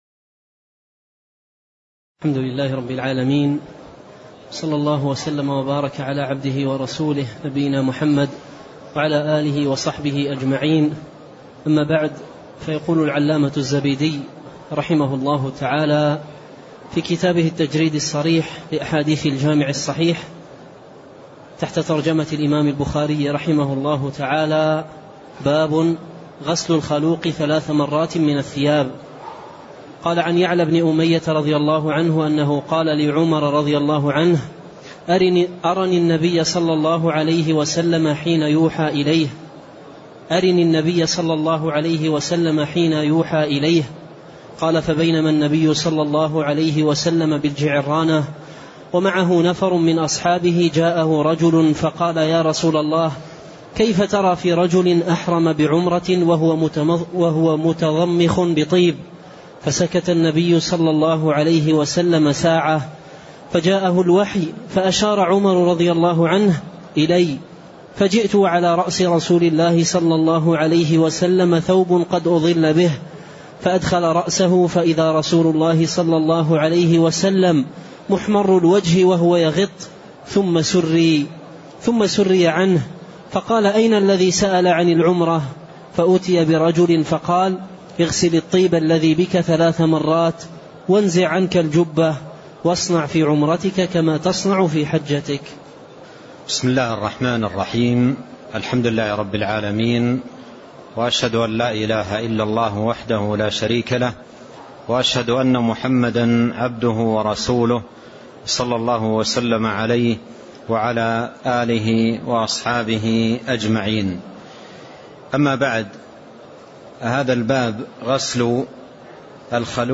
تاريخ النشر ١٦ ذو القعدة ١٤٣٤ هـ المكان: المسجد النبوي الشيخ